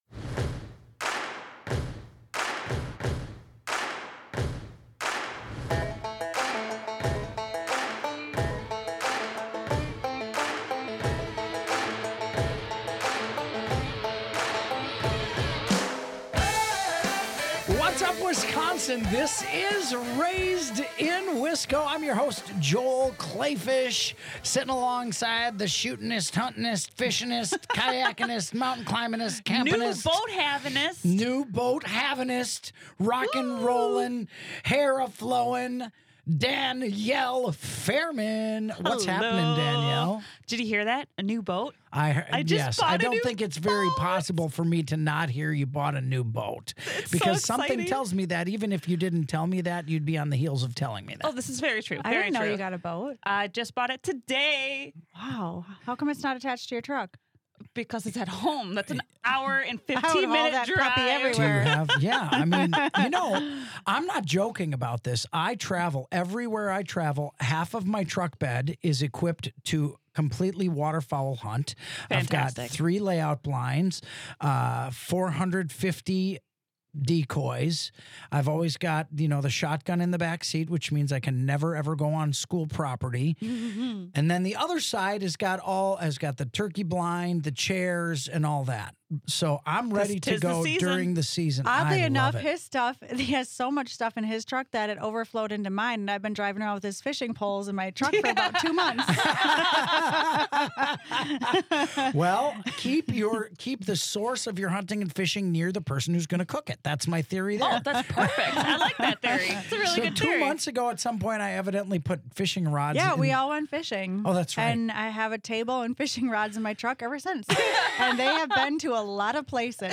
joining us live in studio! We're going to talk about all the most important things there are in life; squirrel hunting, squirrel skinning, competitive bird doggin', and of course squirrel eating.